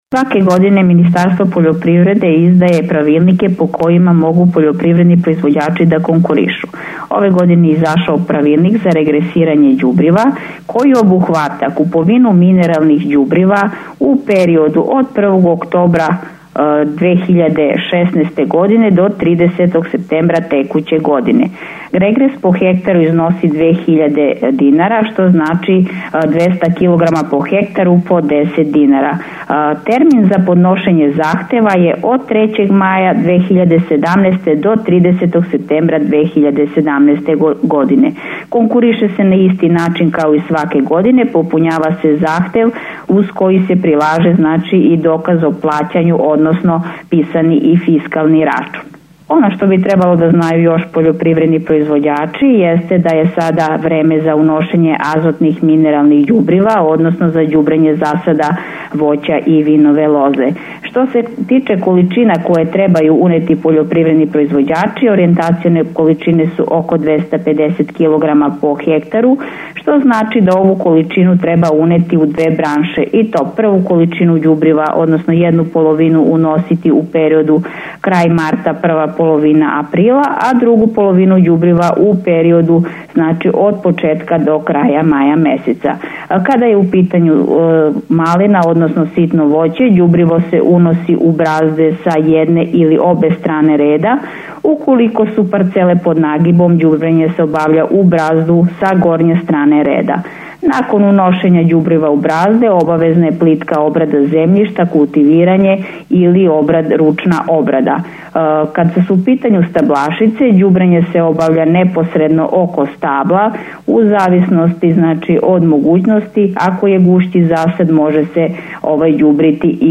Prilog koji emitujemo u Dnevniku, možete preslušati i na našem portalu: